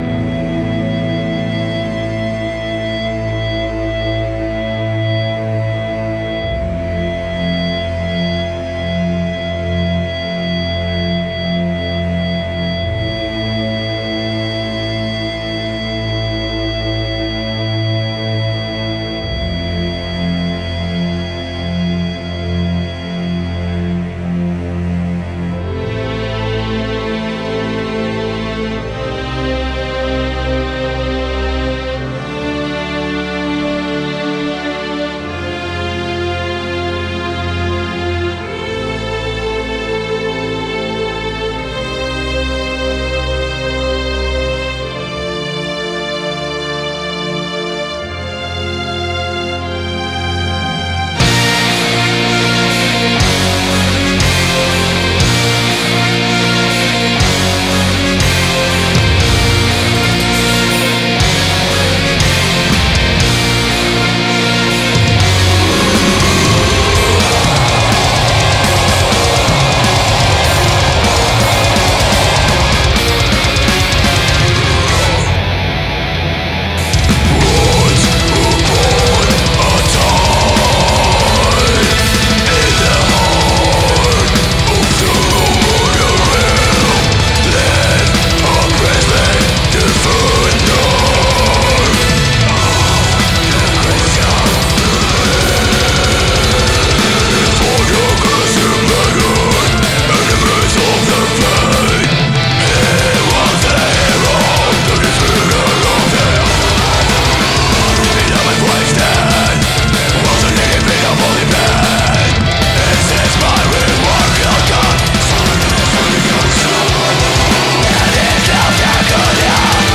Black Metal from Cuba